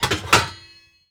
metal_lid_movement_impact_01.wav